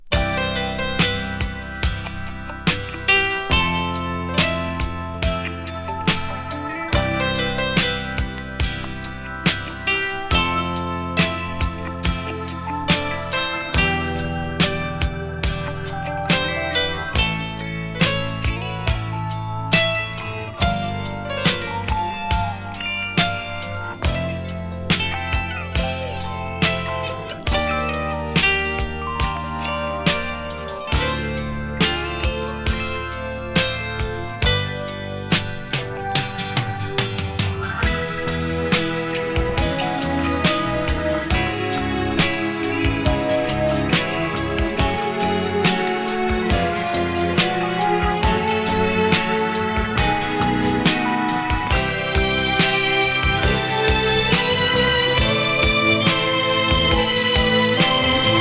サントラからのカット
ハードボイルド感アリのミッドテンポ・インスト